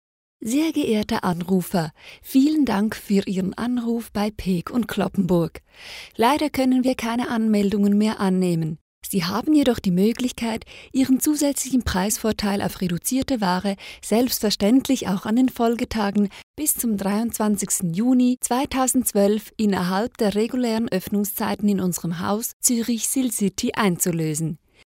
Professionelle Sprecher und Sprecherinnen
Schweizerisch
Weiblich